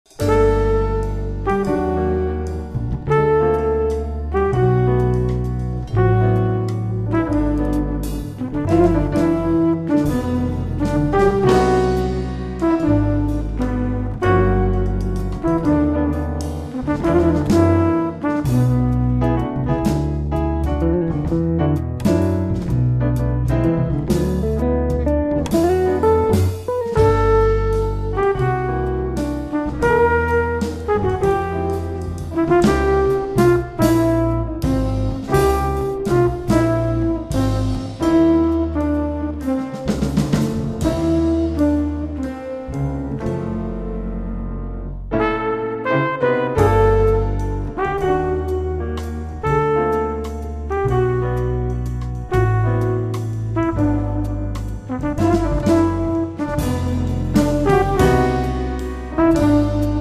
batteria
brano dal tema d'ispirazione quasi cinematografica